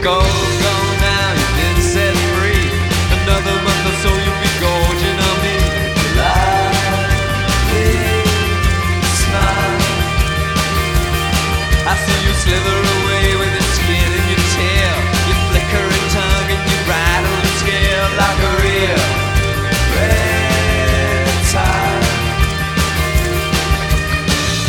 I truly wished this would’ve turned out great as no official instrumental exists, but I can’t exactly fault the algorithms for not being able to track the vocals all that well, as this track has a lot of guitars and other stuff going on.